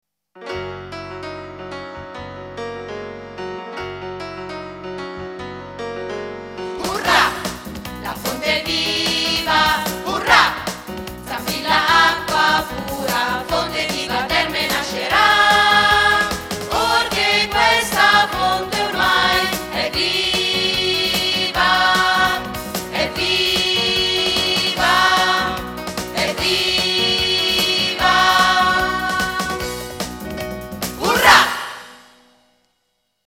Trovate in questa sezione alcuni dei brani cantati durante lo spettacolo.